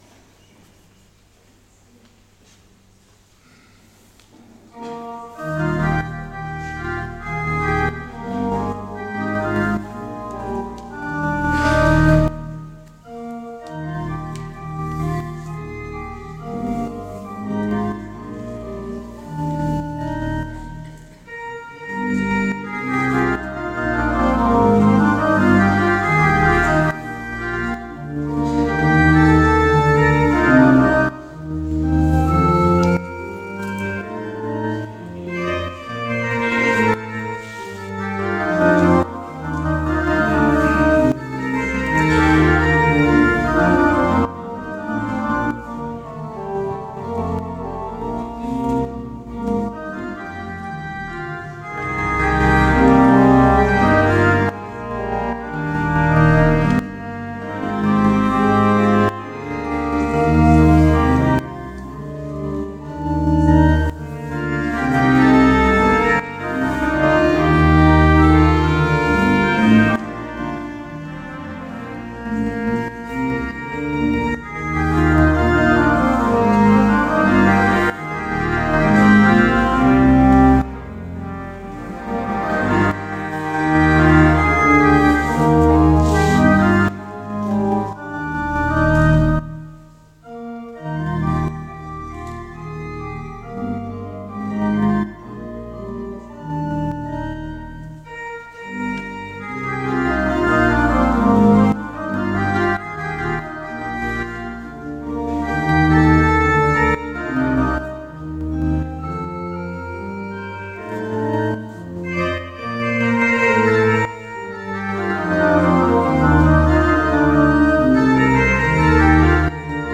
Aktuelle Predigt
Gottesdienst vom 25.01.2026 als Audio-Podcast Liebe Gemeinde, herzliche Einladung zum Gottesdienst vom 25. Januar 2026 in der Martinskirche Nierstein als Audio-Podcast.